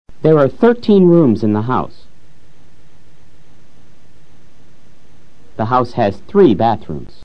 Unas veces suena como la d de dedo o dime.
Otras veces suena como la z española en zoológico: Ejemplo: three (tres).
Este es un primer audio para practicar ambos sonidos: